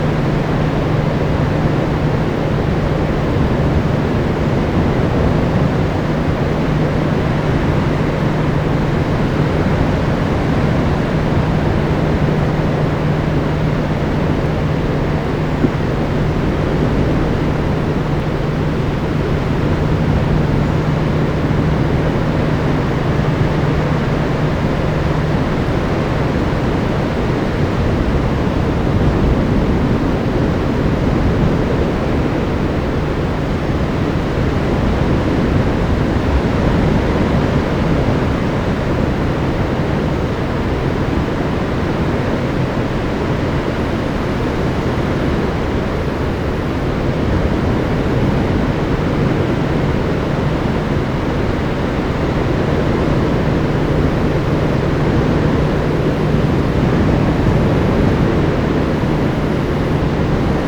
I also made some sound recordings along the beach.
surfstcatherinesjune2014.mp3